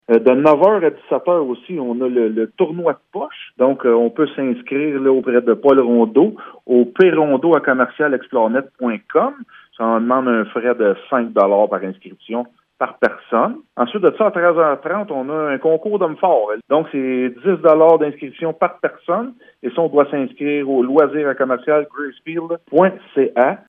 Le maire de Gracefield, Mathieu Caron, parle du tournoi de poches et du concours d’hommes forts :